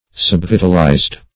Search Result for " subvitalized" : The Collaborative International Dictionary of English v.0.48: Subvitalized \Sub*vi"tal*ized\, a. Imperfectly vitalized; having naturally but little vital power or energy.